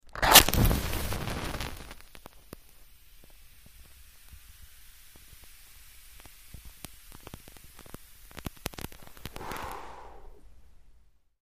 Sizzle; Match Strike, Ignite Flame And Blowout Close Perspective #6-7: Very Close Strike And Light, X7